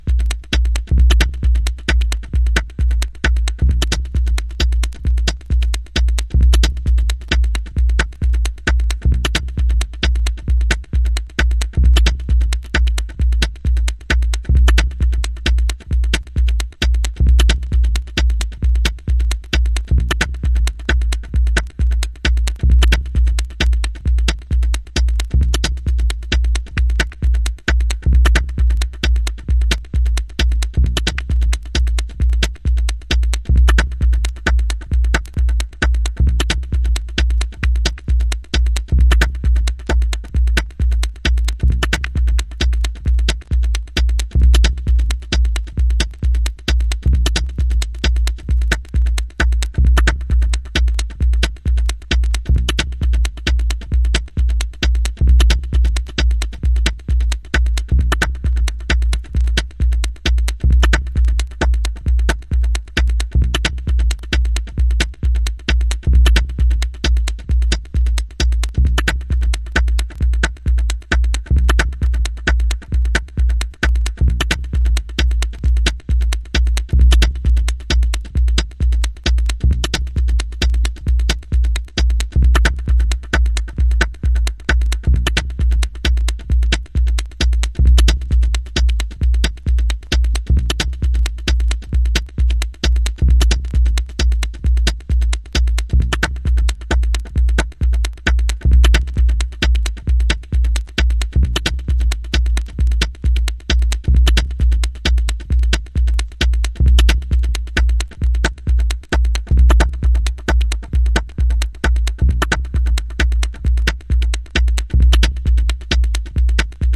Early House / 90's Techno
装飾を一切省き骨組みだけで構成された彼岸の骨ミニマリズム